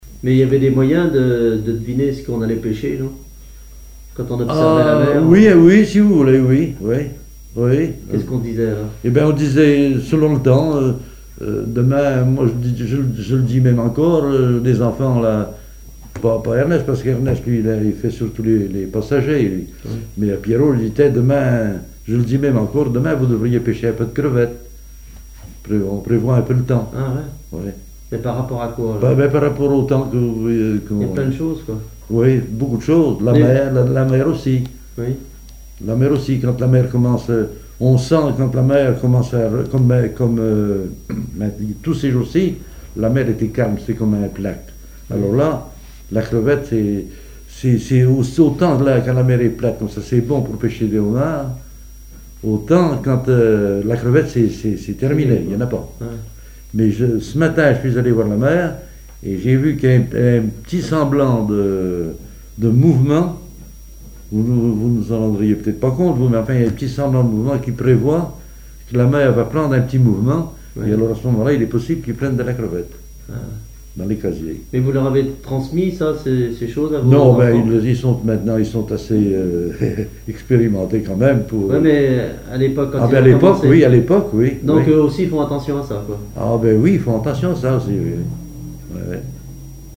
témoignages sur les activités maritimes locales
Catégorie Témoignage